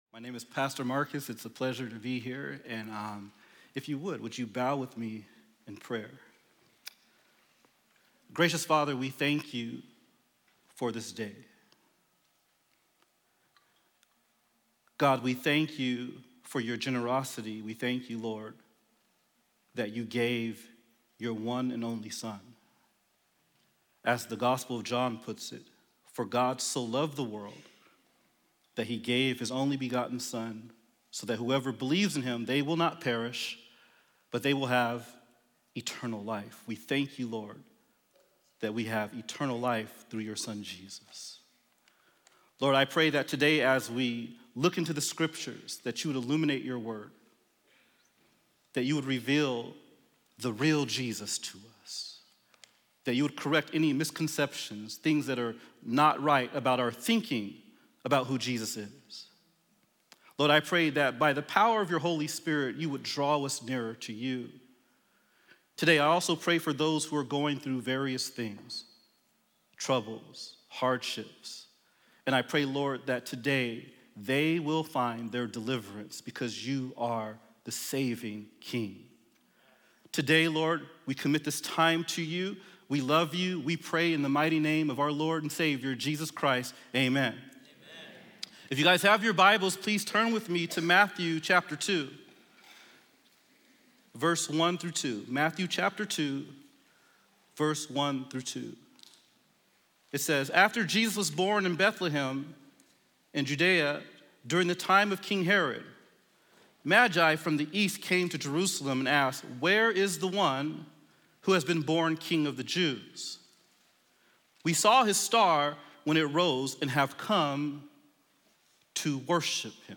Study Guide | Download Audio File Child Dedications Traditional Worship (In-Person Service)